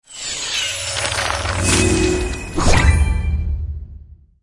c_summon.mp3